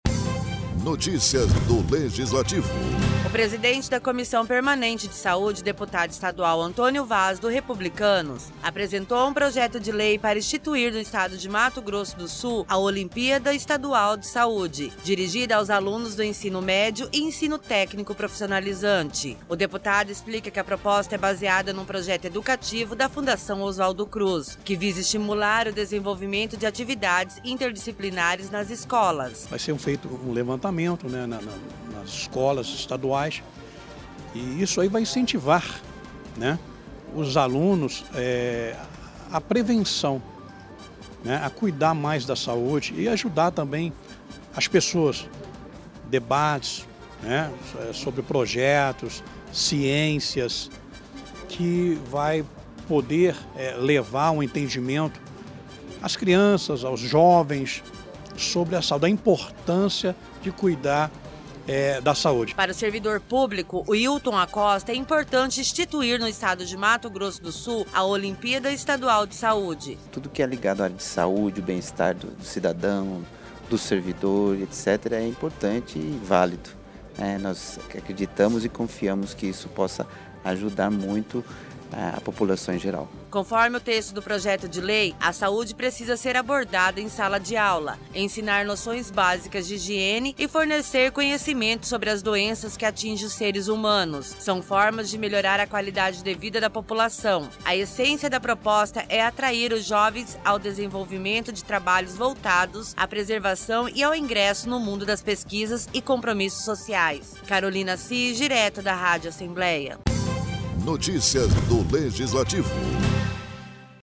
Locução e Produção